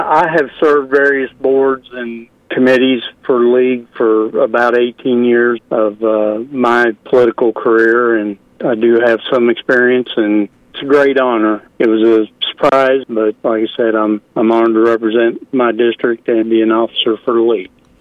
KTLO, Classic Hits and the Boot news spoke with Mayor Braim, who says he is excited about the upcoming year and to be of service to the community.